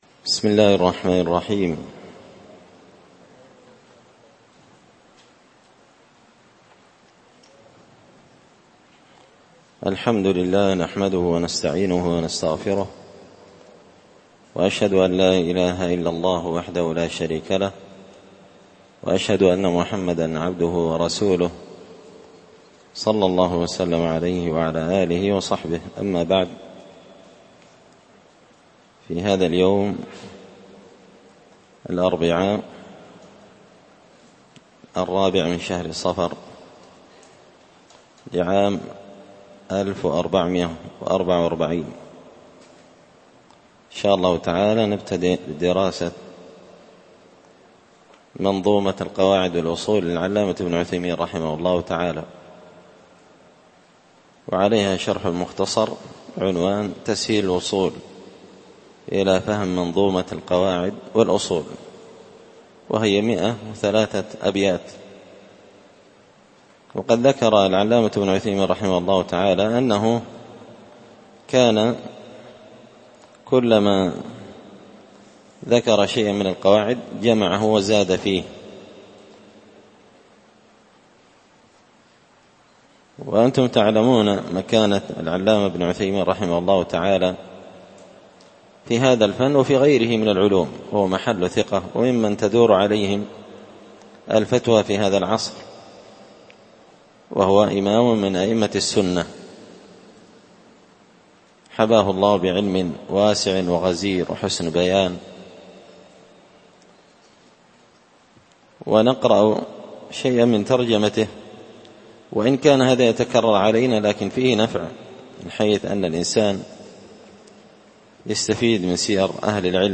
تسهيل الوصول إلى فهم منظومة القواعد والأصول ـ الدرس 1
دار الحديث بمسجد الفرقان ـ قشن ـ المهرة ـ اليمن